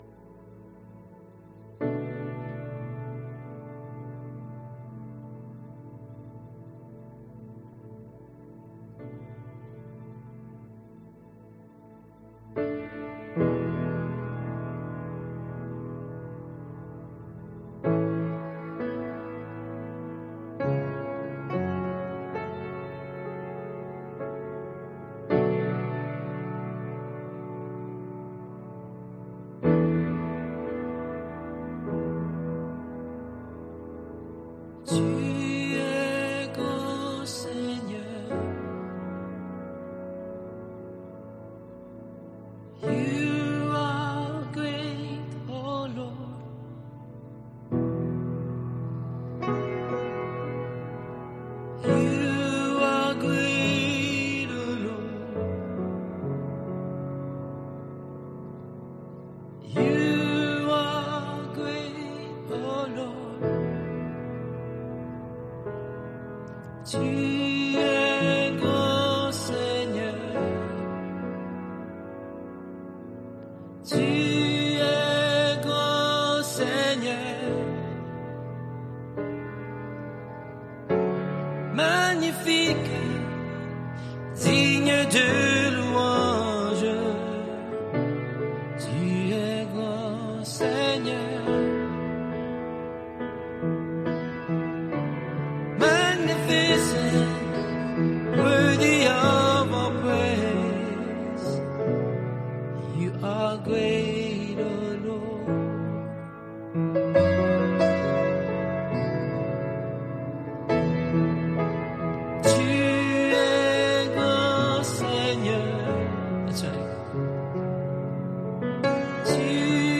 Soaking Prayer and Worship November 11, 2025 -audio only